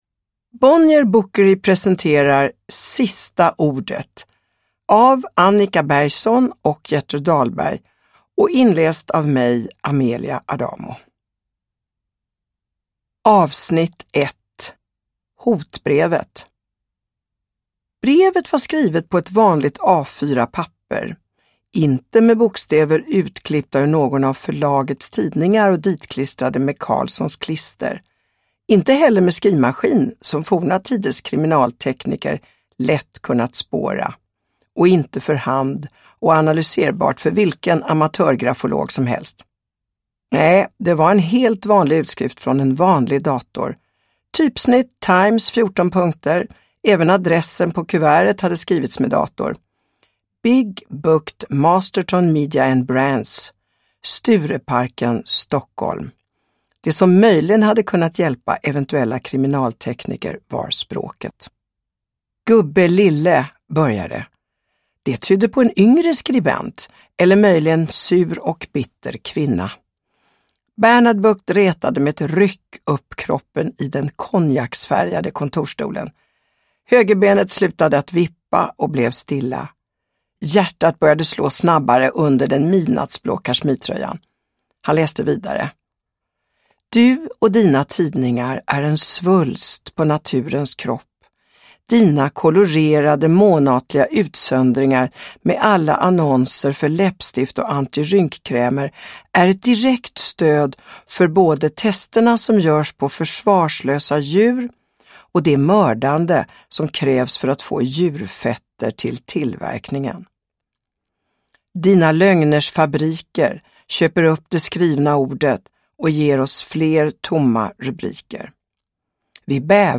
Sista ordet. S1E1, Hotbrevet – Ljudbok – Laddas ner
Uppläsare: Amelia Adamo